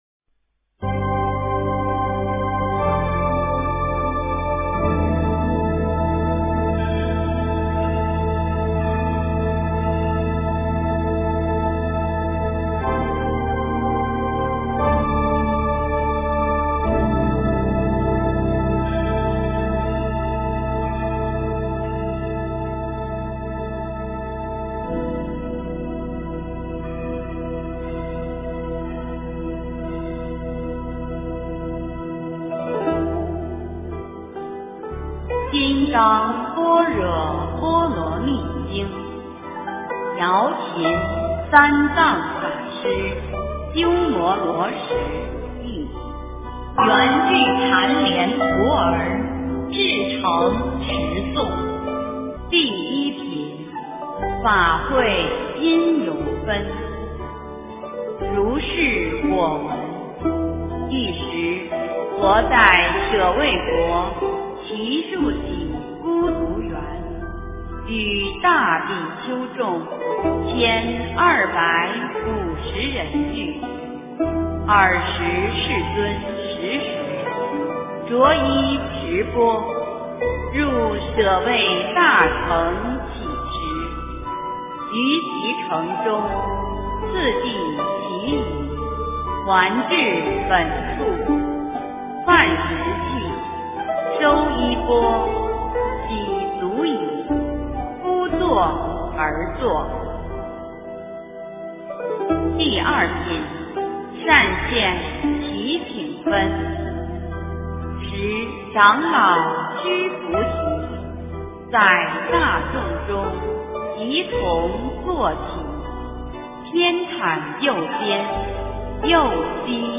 金刚经-标准读诵
诵经